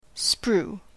/spɹuː(米国英語)/